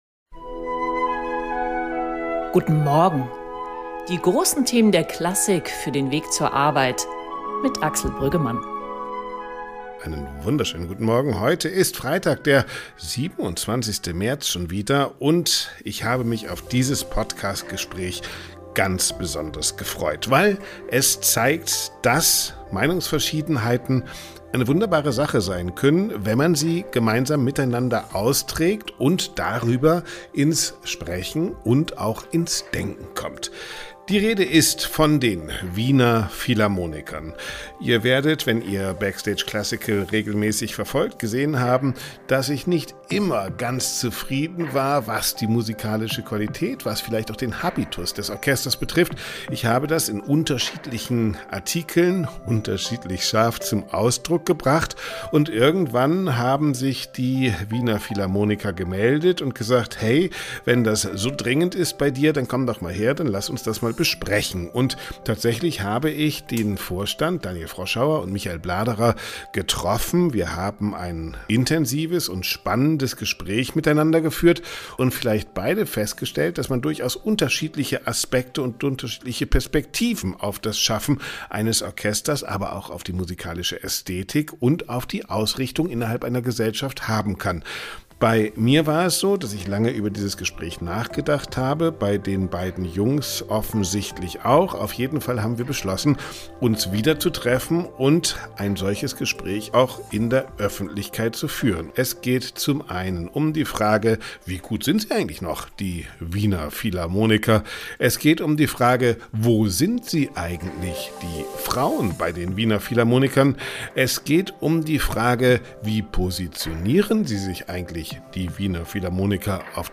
Eine Hymne der Streitkultur: Die Wiener Philharmoniker verteidigen in einem ausführlichen Podcast-Gespräch mit BackstageClassical ihren Kurs gegen Kritik und betonen ihre politische wie künstlerische Eigenständigkeit.